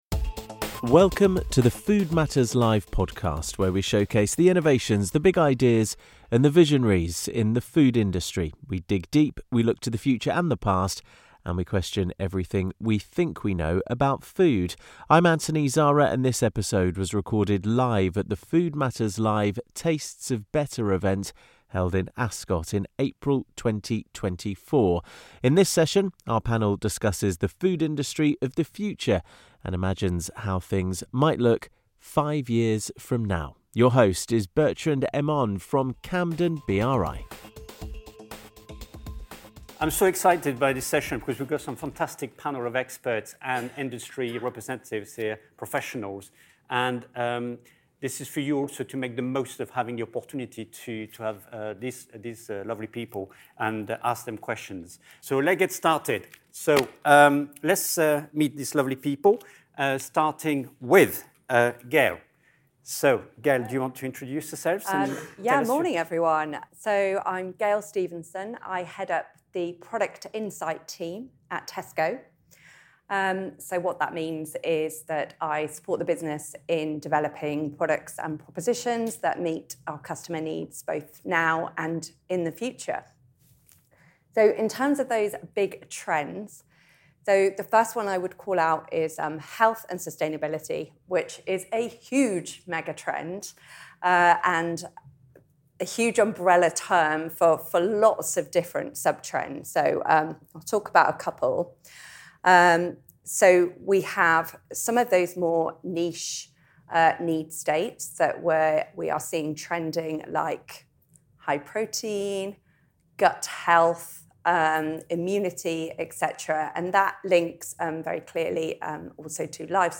In this episode of the Food Matters Live podcast, recorded live at our Tastes of Better event in Ascot in 2024, our expert panel discusses the evolving trends in the food industry. They look to the future and contemplate where we might be heading in terms of health and sustainability, consumer behaviour towards cooking, innovations in retail, personalised nutrition, and the growing importance of allergen awareness.